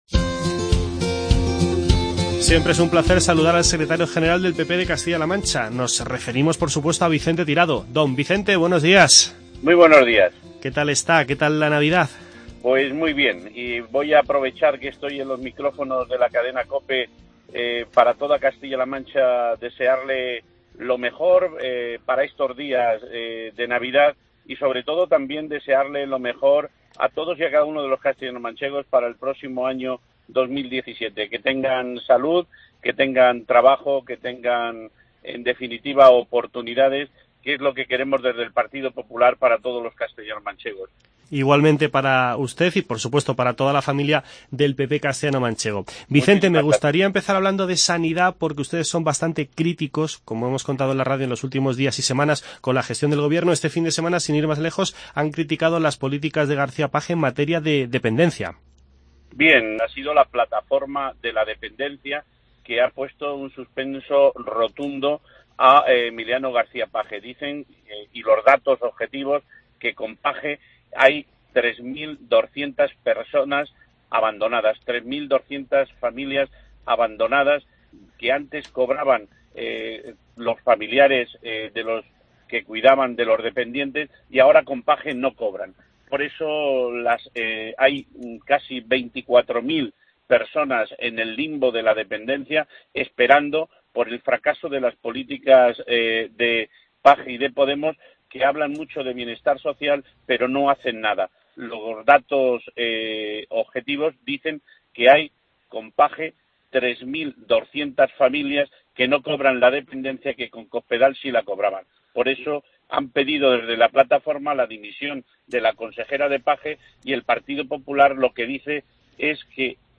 Escuche la entrevista con Vicente Tirado, secretario general del PP de Castilla-La Mancha.